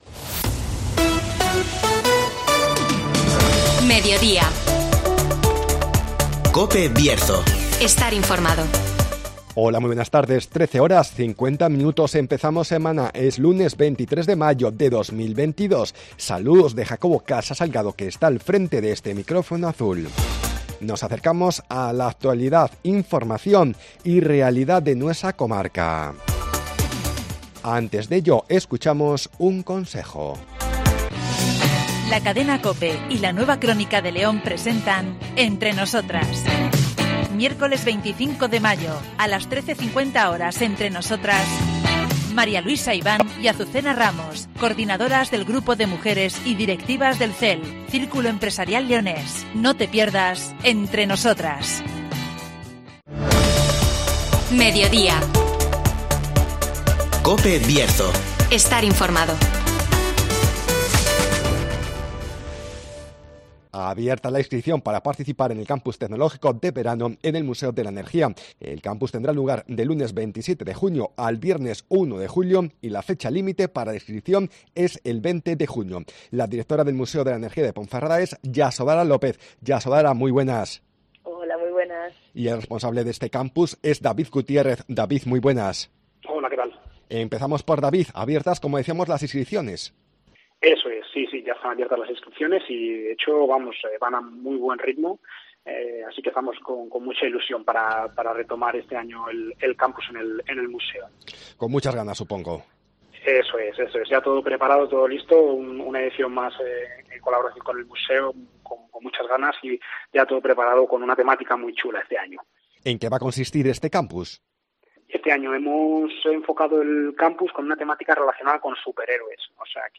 Nos acercamos al Museo de la Energía de Ponferrada (Entrevista